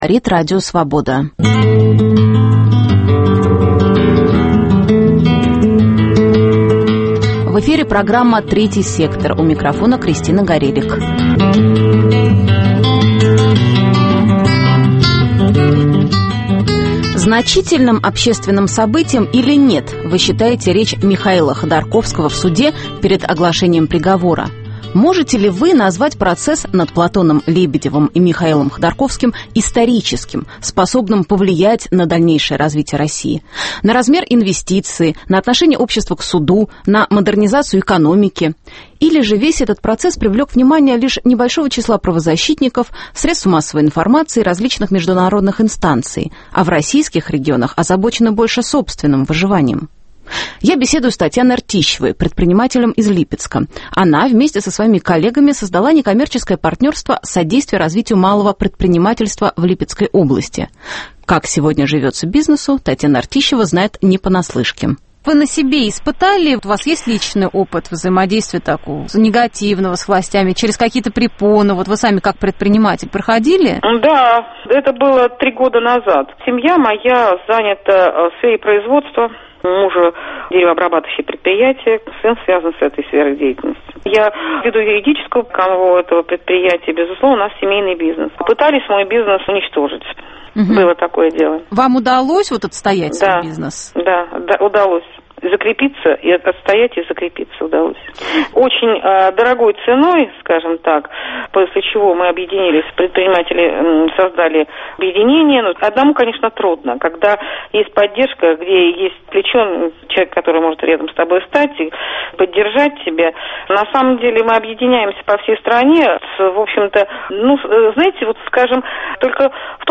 Можно ли назвать процесс над Михаилом Ходорковским историческим, а его речь – значительным общественным событием? О том, повлияет ли приговор суда по этому делу на размер инвестиций в Россию, на модернизацию экономики, на развитие бизнеса говорят представители малого бизнеса, общественных организаций, студенты и прохожие на улице. Во второй части программы прозвучат отрывки из моей архивной передачи «Дорога свободы», посвященной суду над писателями Андреем Синявским и Юлием Даниэлем.